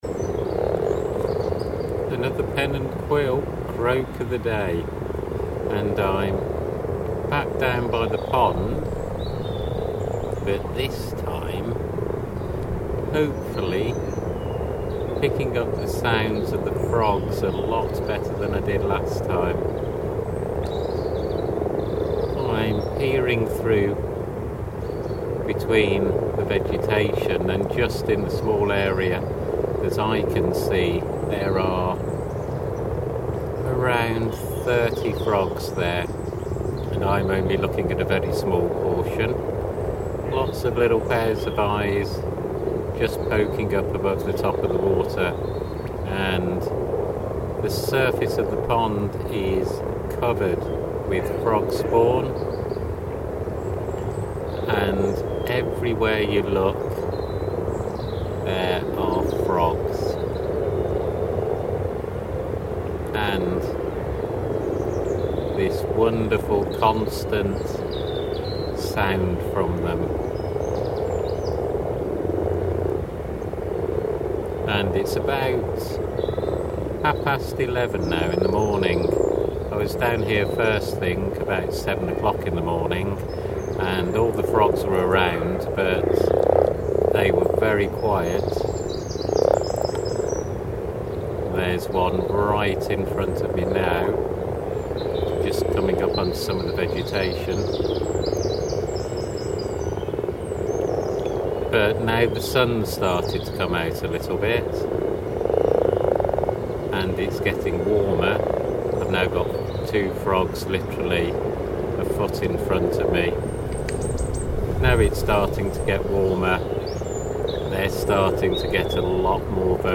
Back at the pond with the common frogs this time with a pair making an appearance right under the mic.